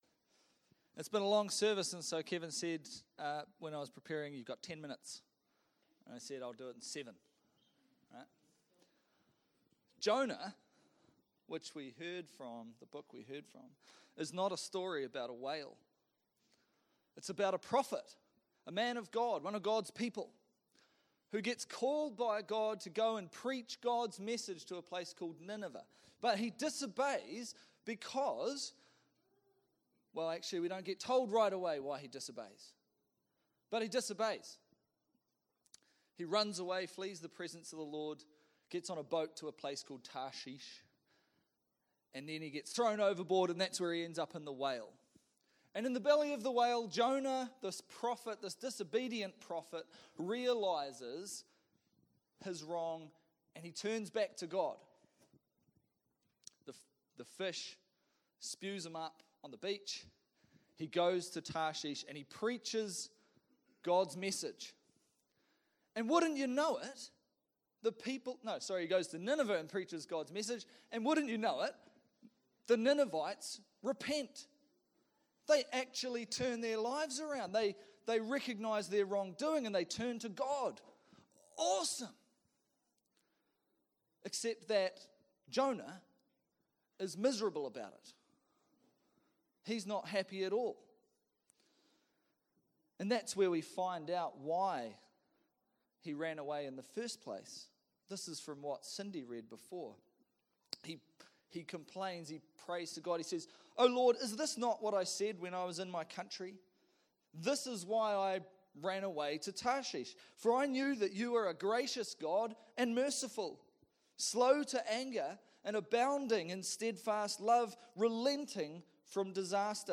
Service Type: Family Service